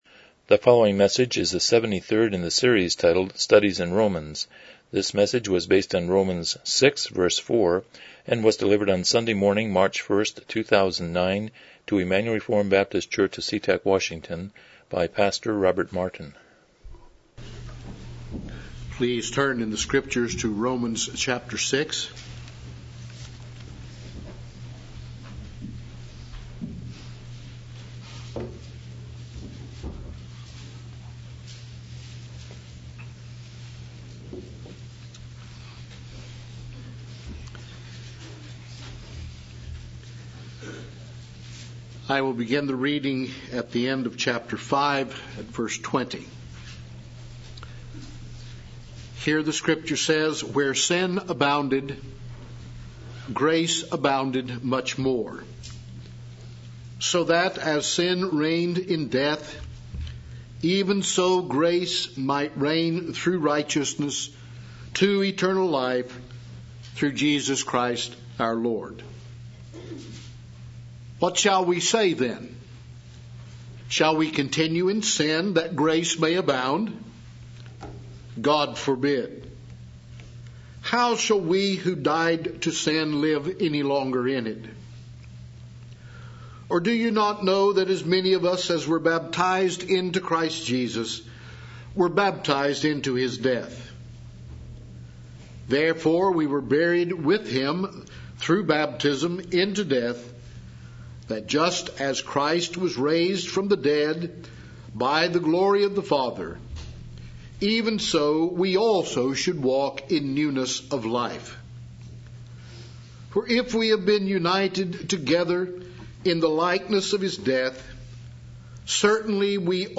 Romans 6:4 Service Type: Morning Worship « 23 Cain and Abel